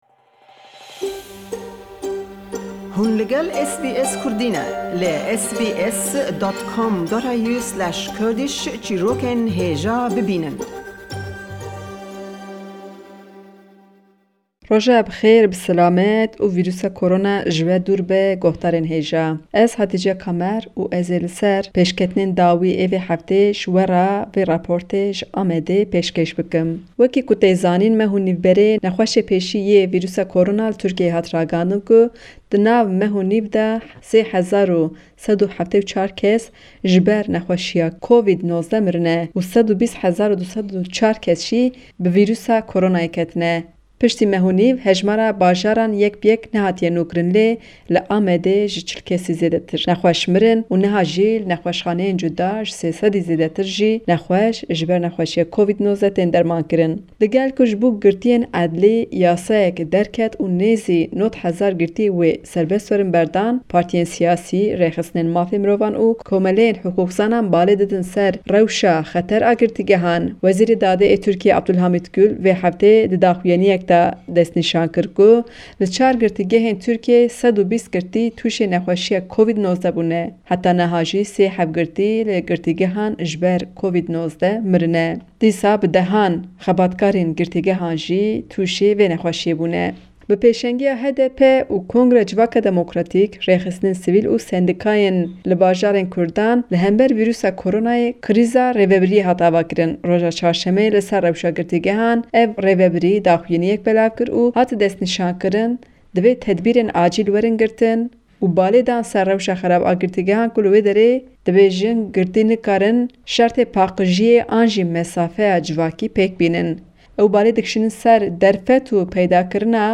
Le raportî em hefteye le Amede we diwa hewallekan bibîste sebaret be vîrusî-korona le Turkîya be giştî û helsûkewtî hukûmetî ew willate le gell ew rewşe.